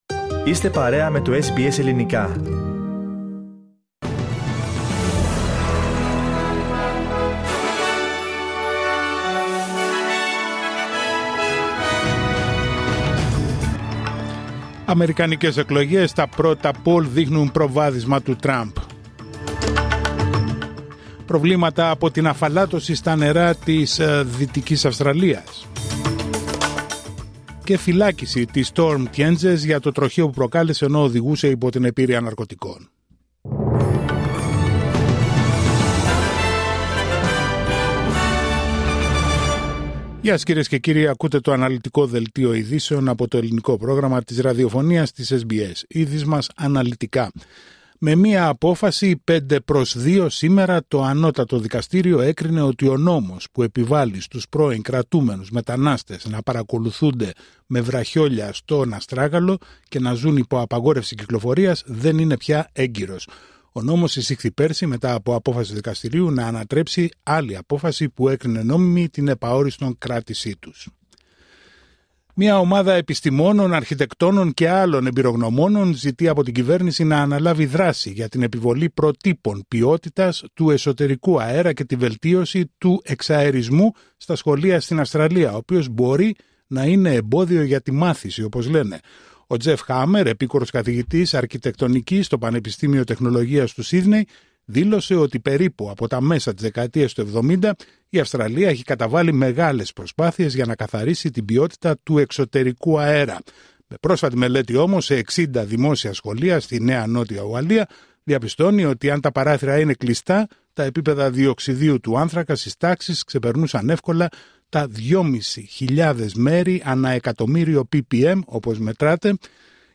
Δελτίο ειδήσεων Τετάρτη 6 Νοεμβρίου 2024